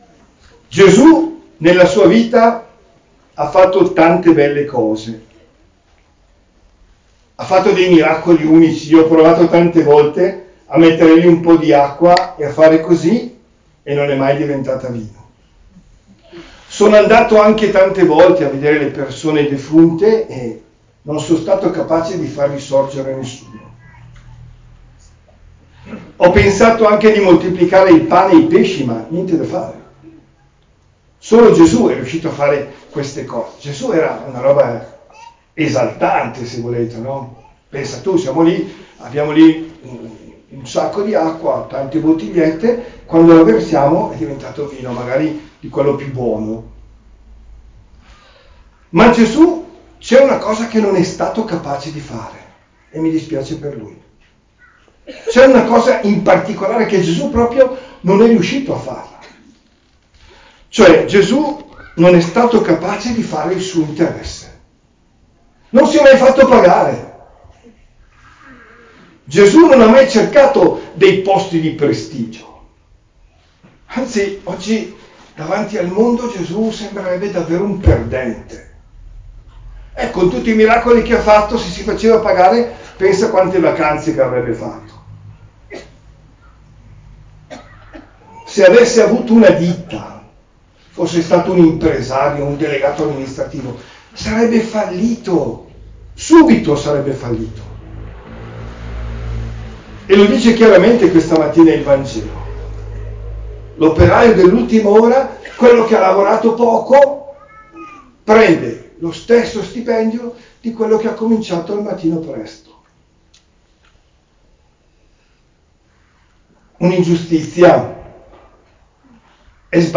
OMELIA DEL 24 SETTEMBRE 2023